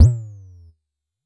Modular Tom.wav